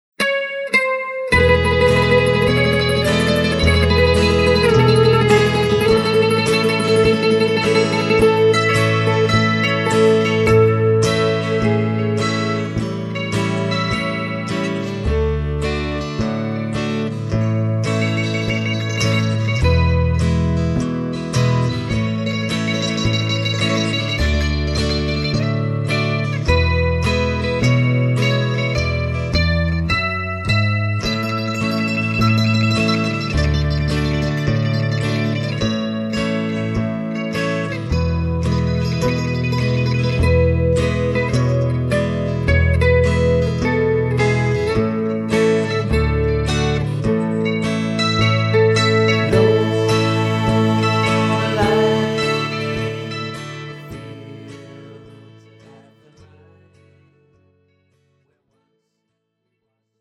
MR 반주입니다.